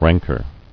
[rank·er]